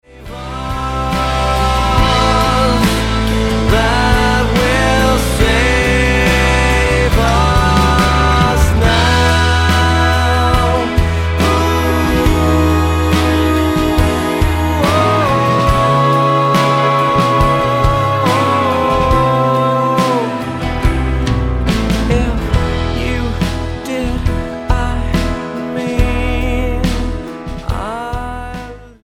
British rock three piece
10187 Style: Rock Approach